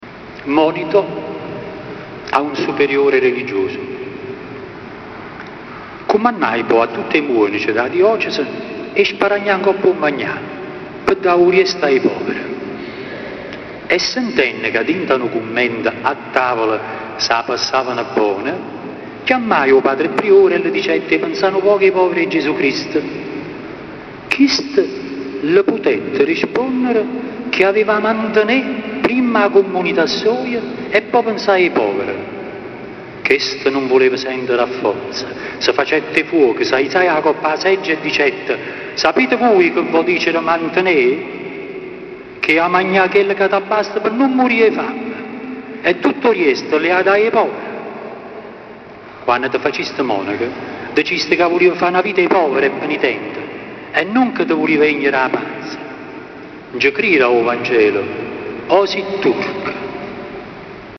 dialetto napoletano
Lettura di alcuni brani
(la registrazione è stata fatta dal vivo con… una macchina fotografica digitale)